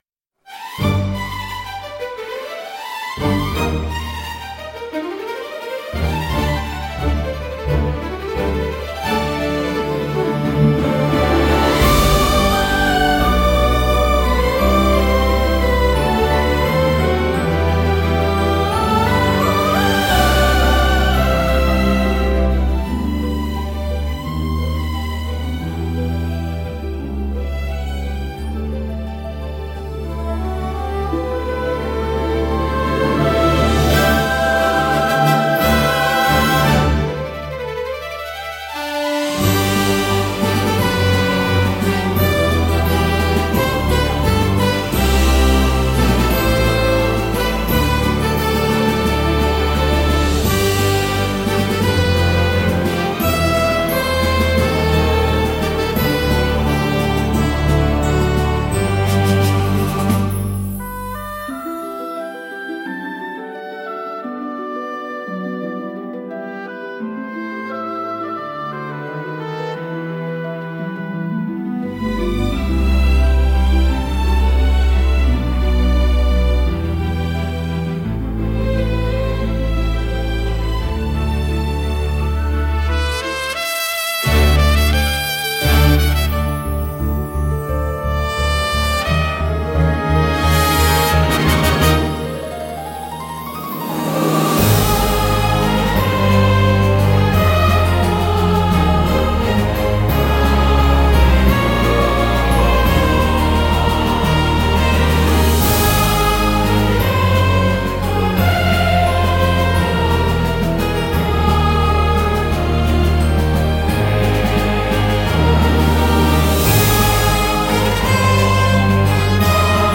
ロマンティックで華やかな場にぴったりのジャンルです。